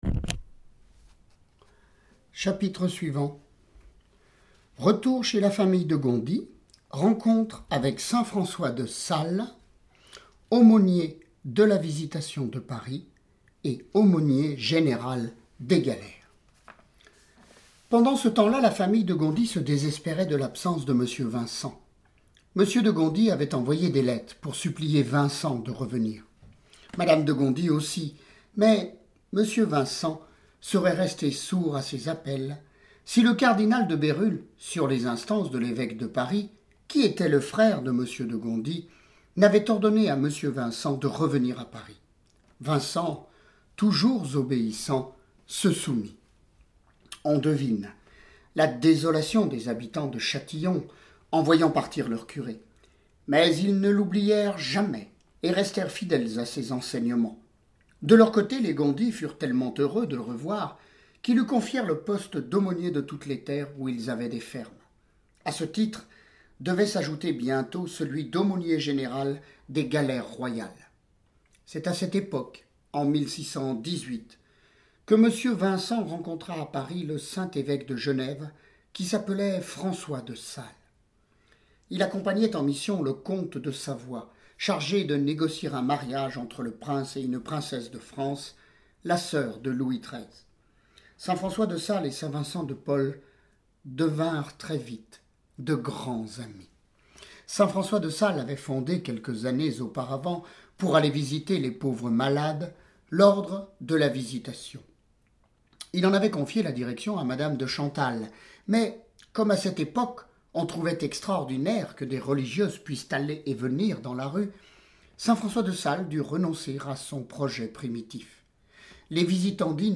Lecture de vie de Saints et Saintes >> Saint Vincent de Paul